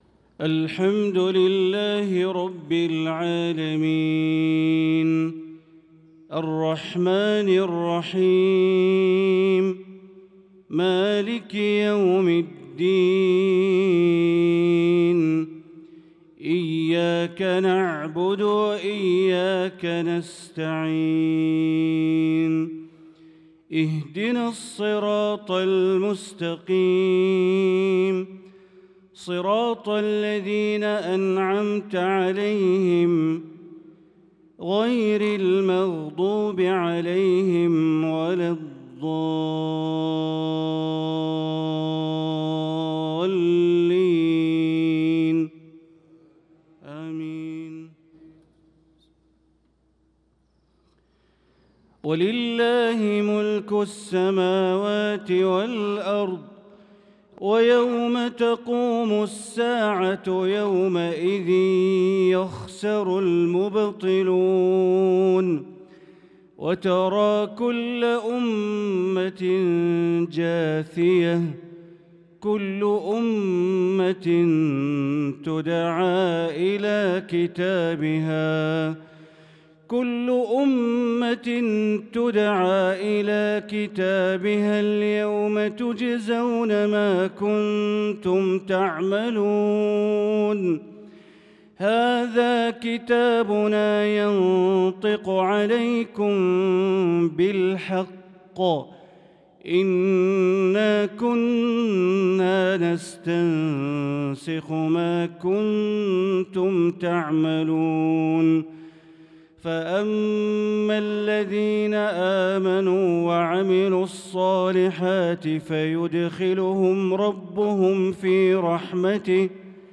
صلاة العشاء للقارئ بندر بليلة 18 شوال 1445 هـ
تِلَاوَات الْحَرَمَيْن .